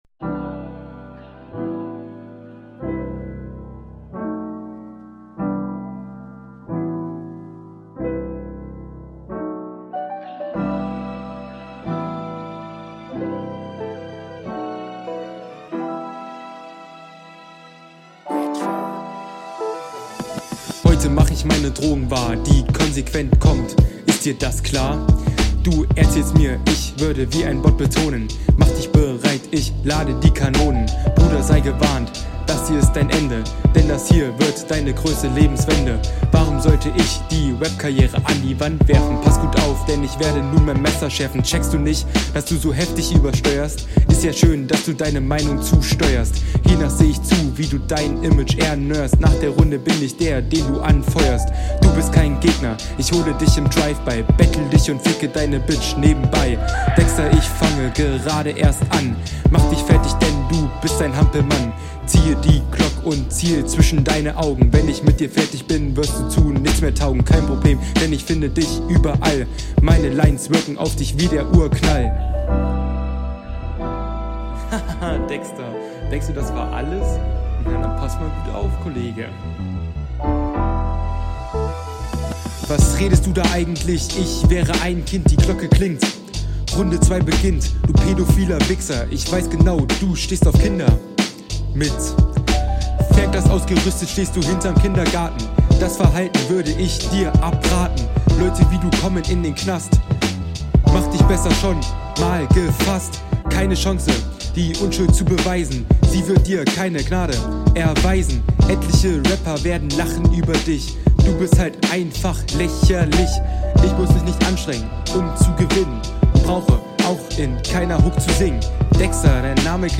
Du klingst wirklich in den ersten halben minute als wenn du ein BOT wärst.